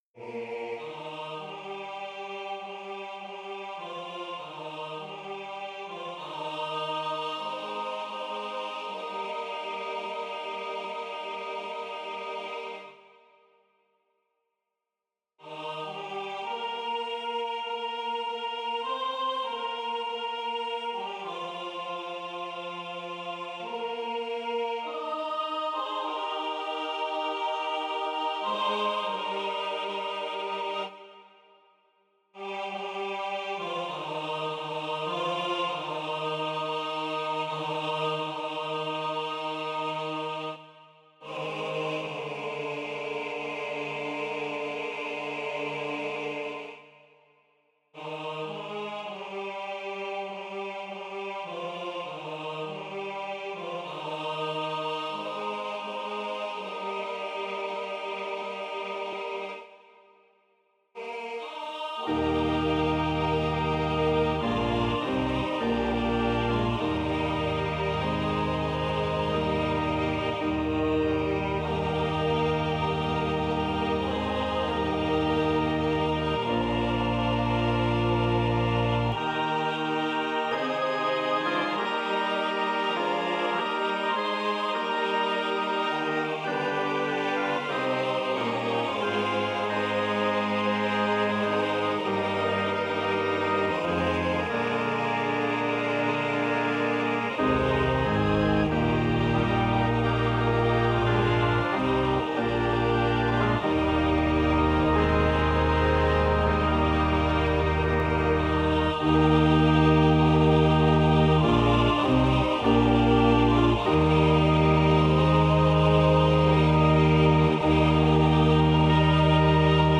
Arrangment for SAB, Organ, and Congregaton. The organ settings and notes are based on the Rogers 790C organ at our chapel.
Voicing/Instrumentation: SAB , Organ/Organ Accompaniment We also have other 4 arrangements of " Were You There?
Choir with Congregation together in certain spots